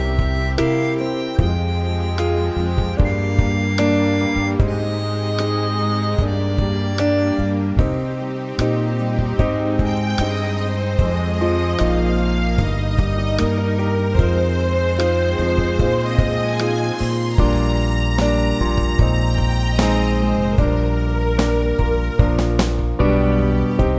Backing Vocals Softened Pop (1970s) 3:35 Buy £1.50